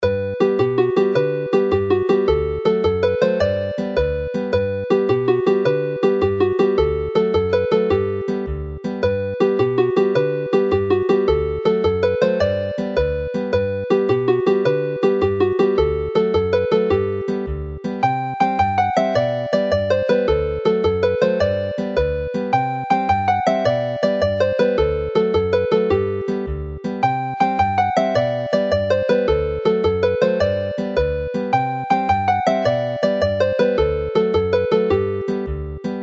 Alawon traddodiadol Cymreig - Set Hela'r Sgyfarnog, gyda Cainc Dafydd ap Gwilym a Ty a Gardd